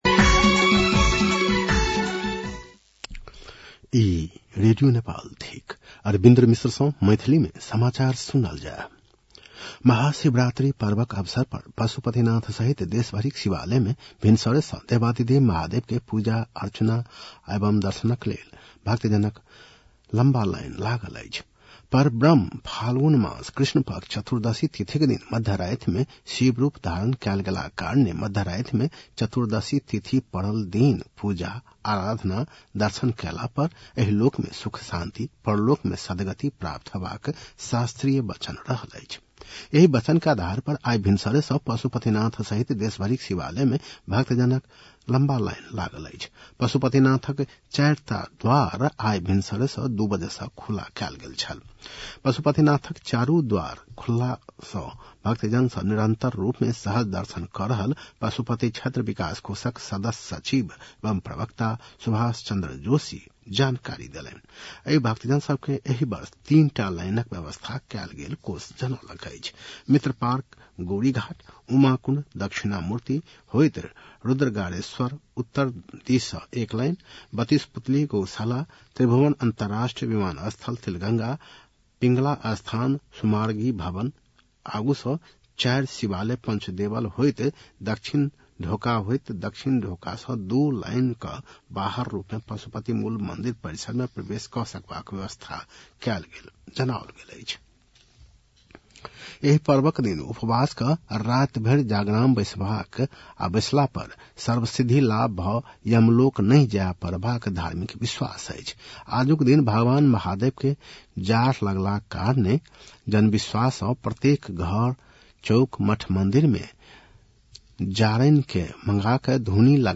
मैथिली भाषामा समाचार : ३ फागुन , २०८२
6.-pm-maithali-news-1-6.mp3